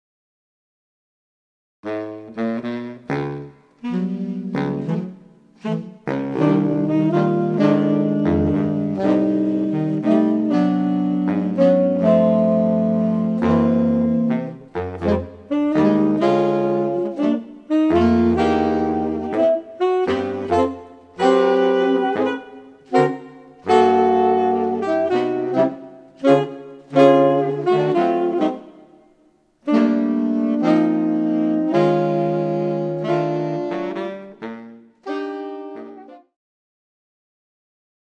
Categories » Saxophone » Saxophone Quartets
Thick harmonies throughout.